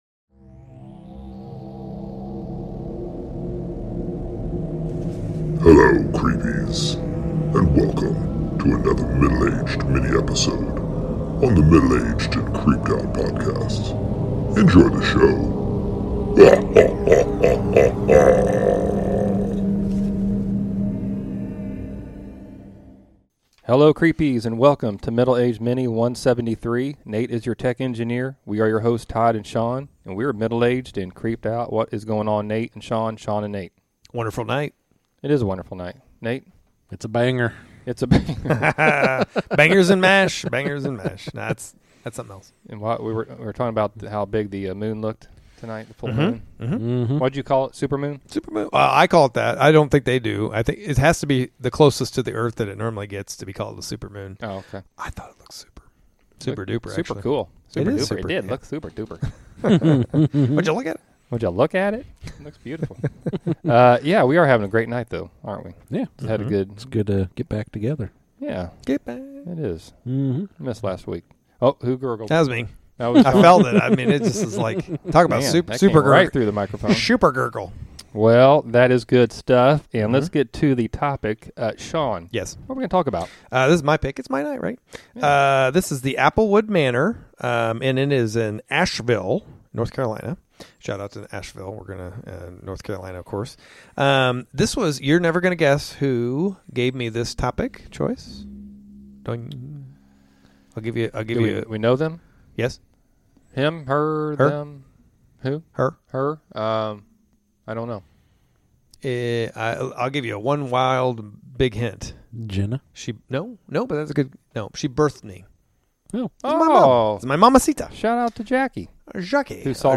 The guys have a crazy and creepy discussion on this historic North Carolina inn...Haunted Applewood Manor (Asheville)!!!